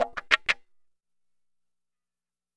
WAHKACHAK2-R.wav